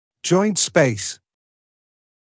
[ʤɔɪnt speɪs][джойнт спэйс]суставная щель